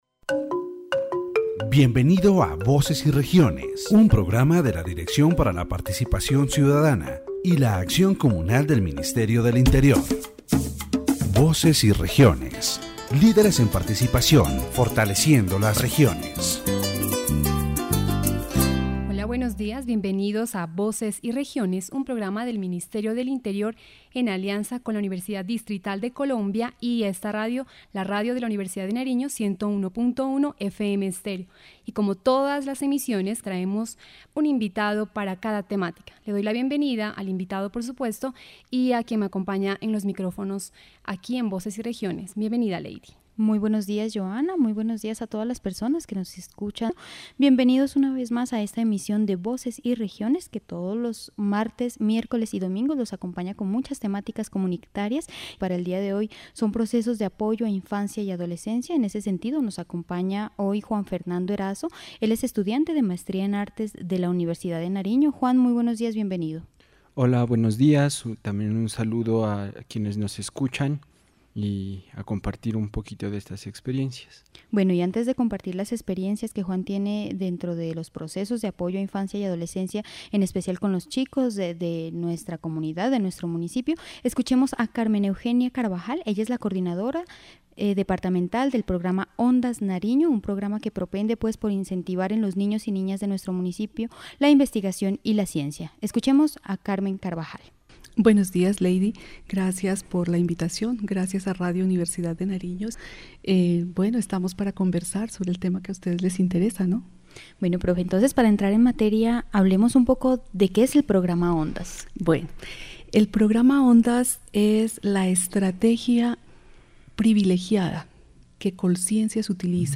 The radio program "Voices and Regions" is positioned as a fundamental space to make visible and promote initiatives that contribute to the comprehensive development of children and adolescents. Through the participation of experts and community leaders, various strategies are explored to encourage research, creativity, and the active participation of young people.